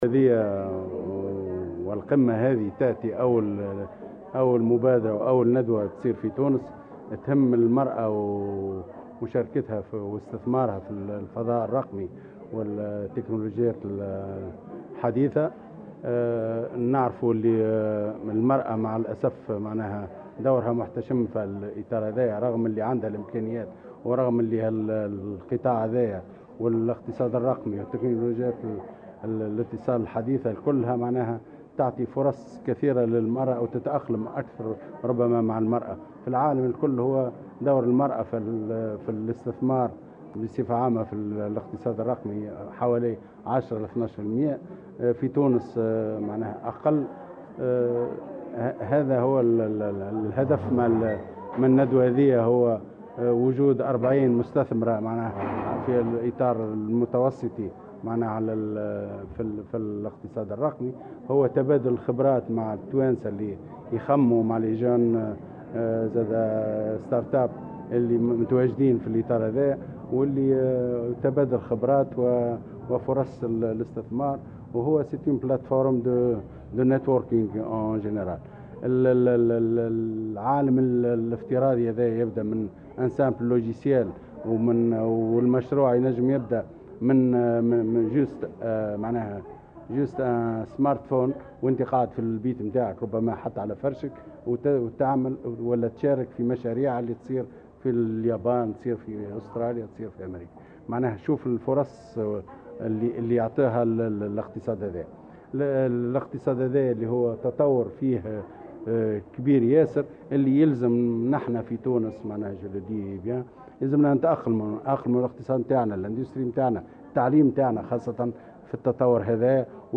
قال المستشار الأول لدى رئيس الجمهورية المكلف بالأمن القومي، كمال العكروت على هامش انطلاق اشغال القمة الرقمية للمراة في منطقة المتوسط، إن حجم الاستثمارات النسائية في الاقتصاد الرقمي يعتبر ضعيفا، على الرغم من الفرص المتاحة أمامها.